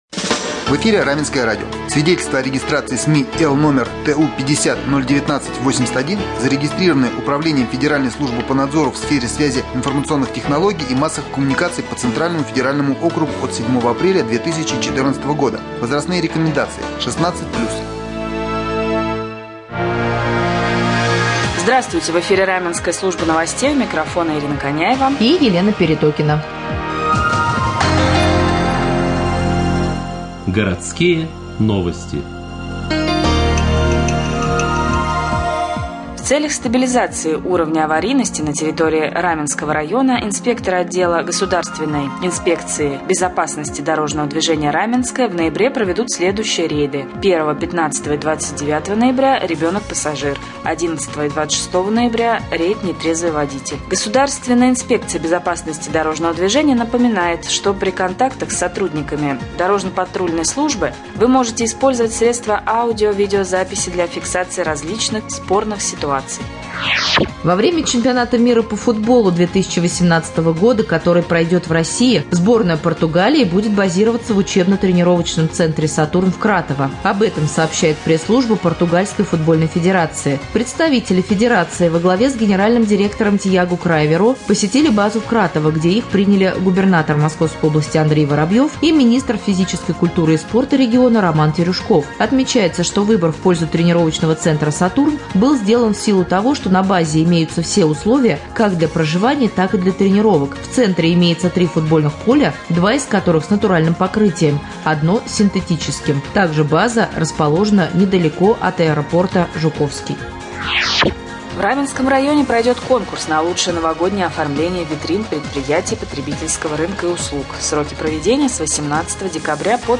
Сегодня в новостном выпуске на Раменском радио Вы услышите, где в Раменском районе будет базироваться сборная Португалии во время Чемпионата мира по футболу 2018, какие рейды проведут сотрудники ГИБДД на территории Раменского района в ноябре, как стать участником конкурса на лучшее новогоднее оформление витрин, а также последние областные новости и новости соседних районов.